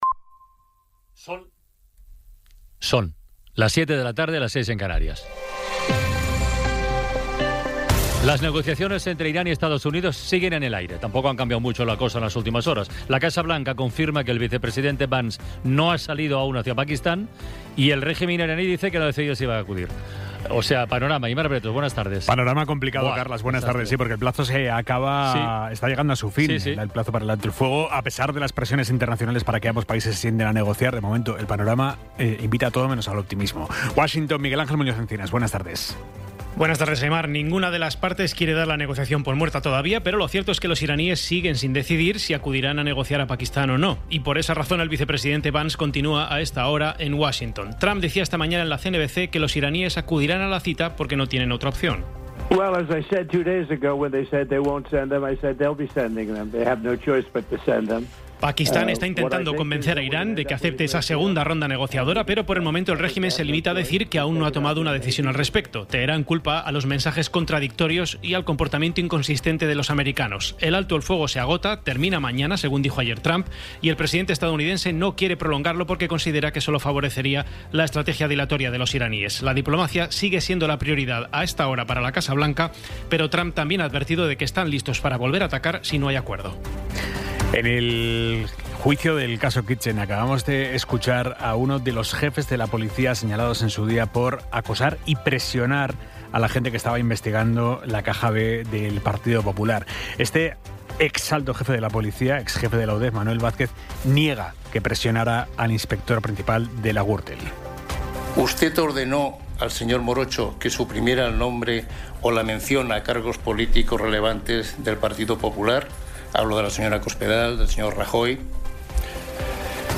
Resumen informativo con las noticias más destacadas del 21 de abril de 2026 a las siete de la tarde.